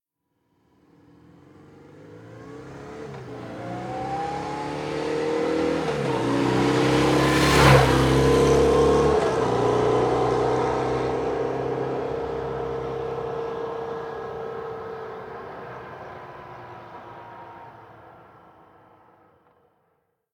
Moto marca BMW pasando rápidamente
motocicleta
Sonidos: Transportes